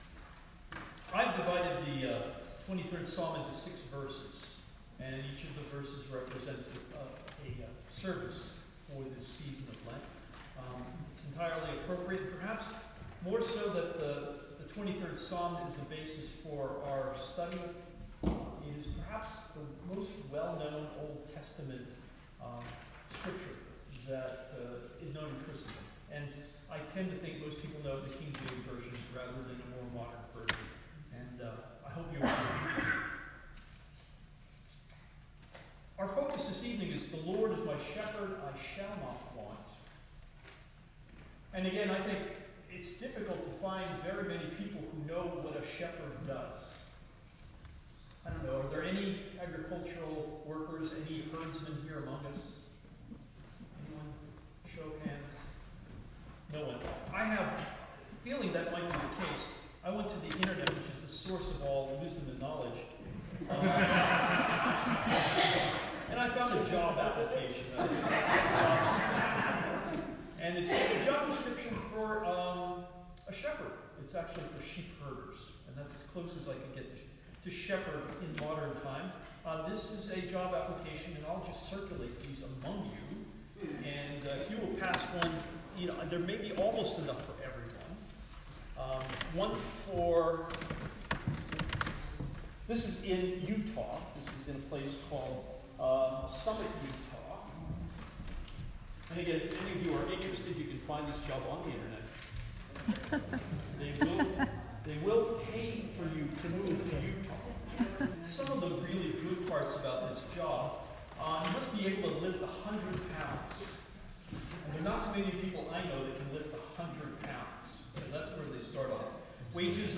Wednesday-Evening-Sermon-week1-CD.mp3